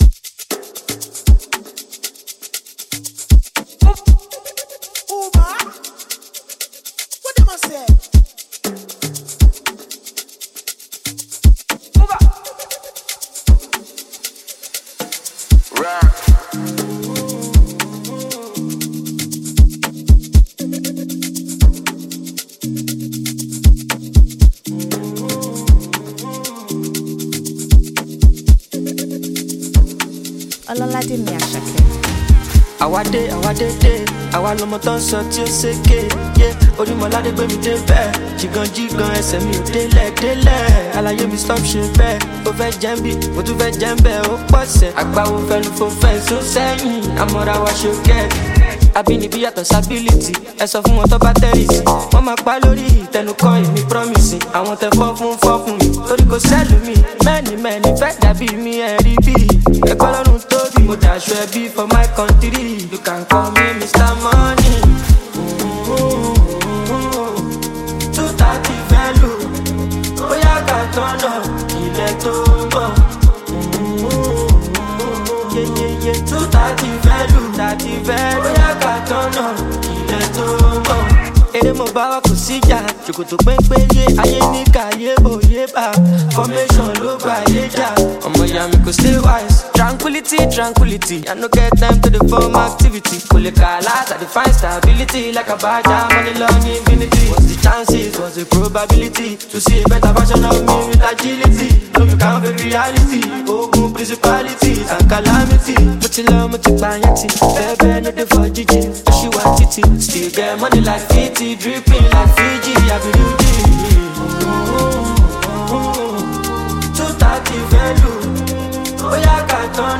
Amapiano-infused single
is an uptempo record
delivers a catchy song